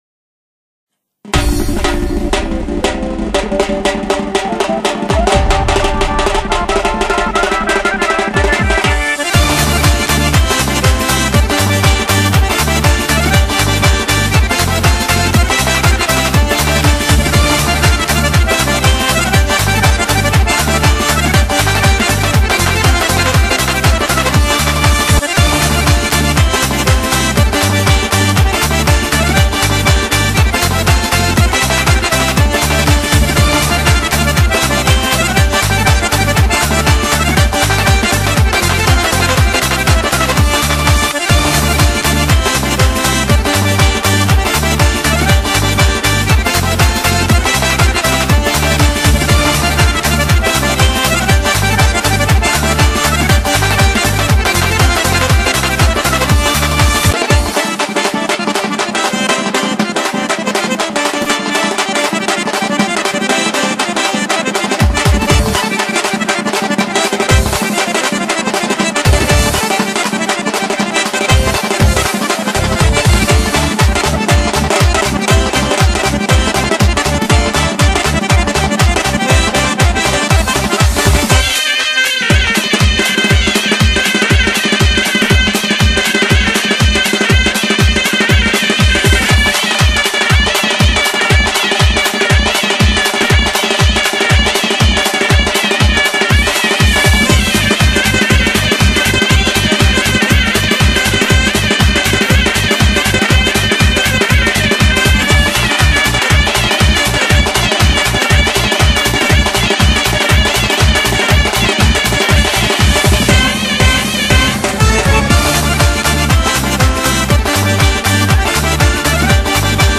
Лезгинка музыка без слов